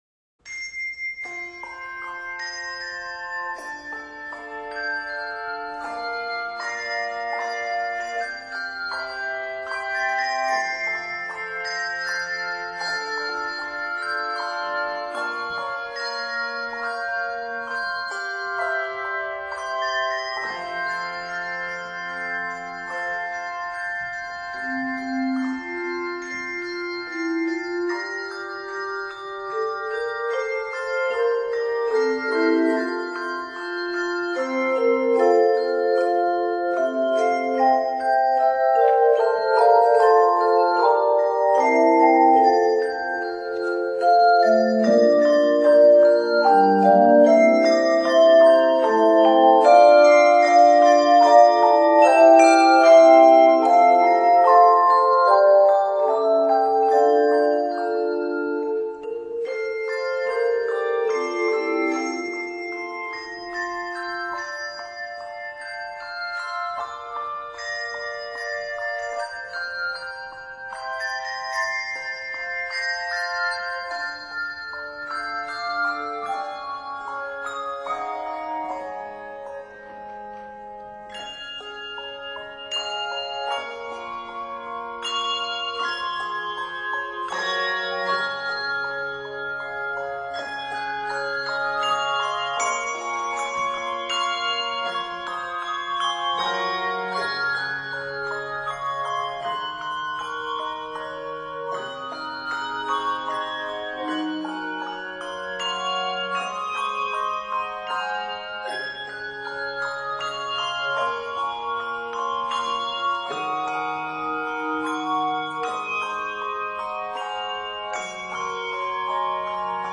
It is set in the keys of F Major and Db Major.